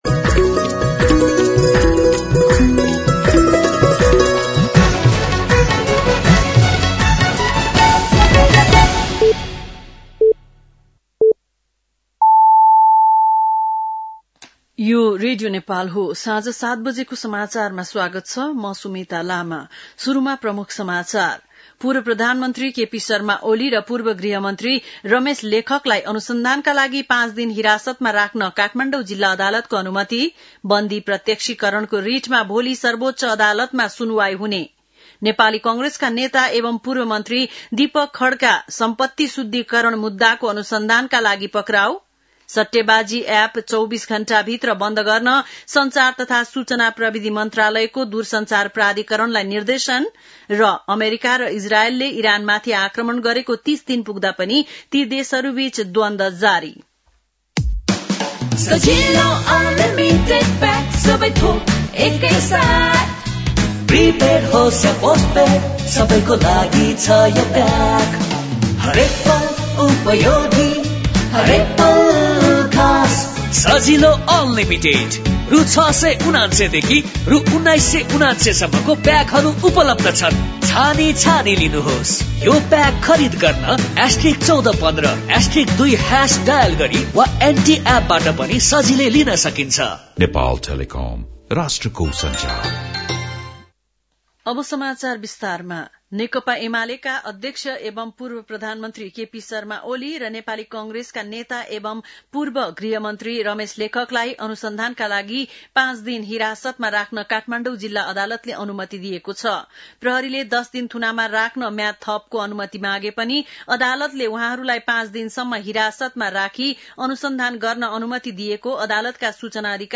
बेलुकी ७ बजेको नेपाली समाचार : १५ चैत , २०८२
7-pm-nepali-news-1-2.mp3